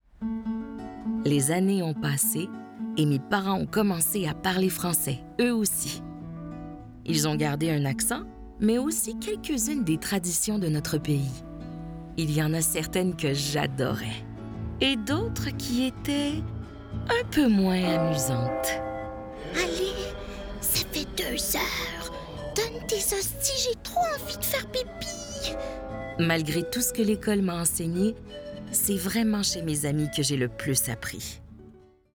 Livre audio – Narratrice et personnage